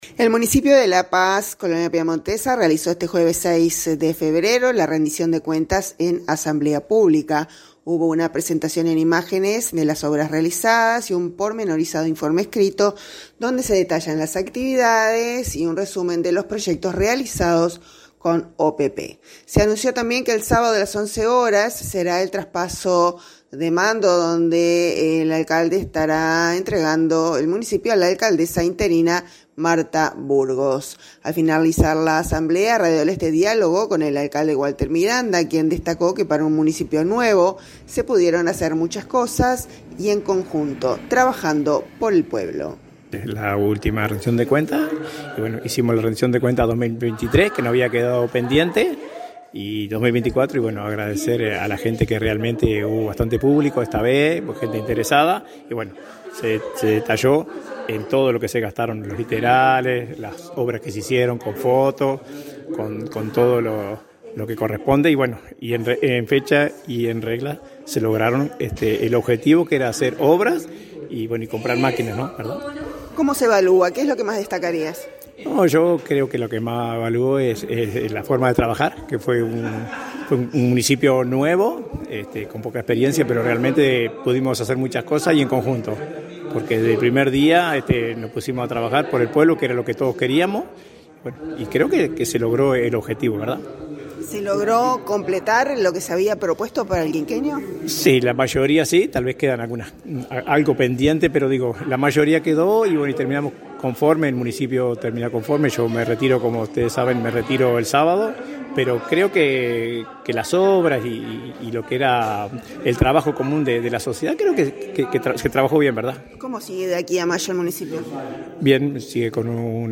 Al finalizar la asamblea, Radio del Oeste dialogó con el Alcalde Walter Miranda, quien destacó que para un Municipio nuevo, se pudieron hacer muchas cosas y en conjunto, trabajando por el pueblo.